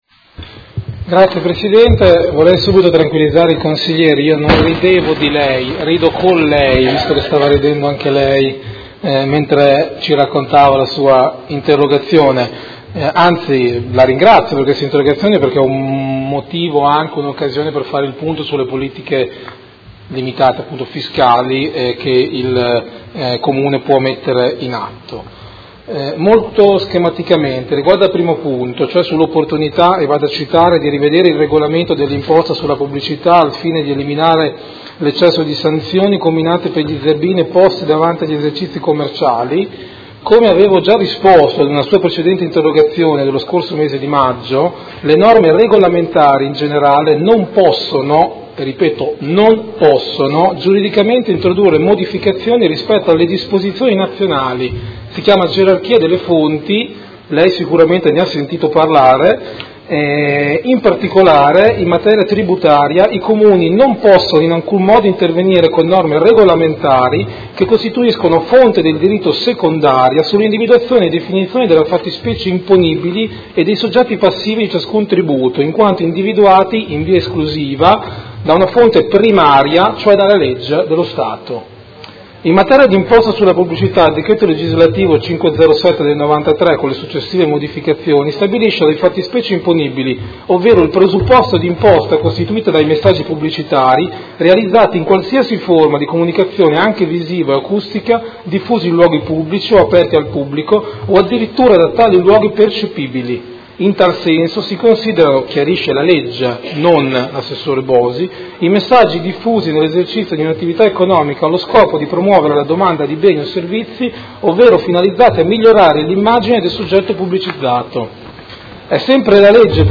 Seduta del 21/12/2017. Risponde a interrogazione del Consigliere Morandi (FI) avente per oggetto: Rivedere e ridurre la tassazione sulle imprese per dare nuovo slancio all'economia cittadina